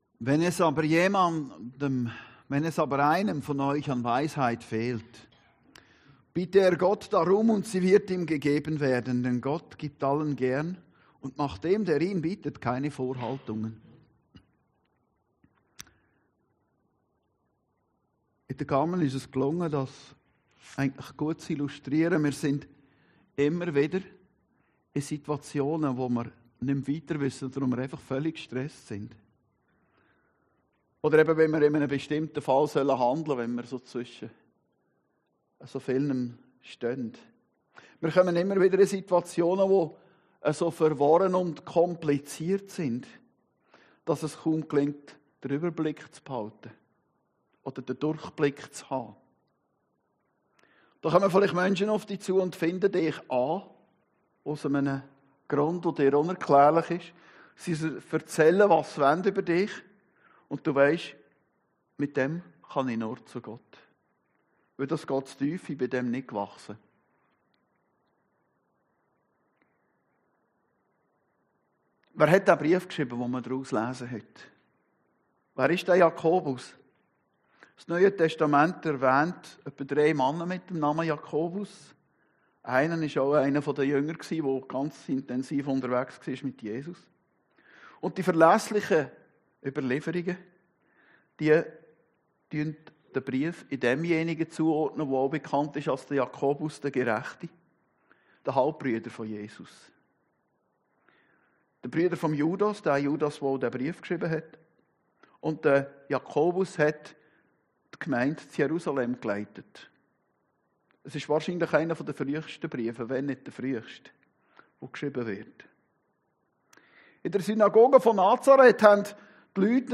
Predigten des Heilsarmee Korps Aargau Süd (Reinach AG)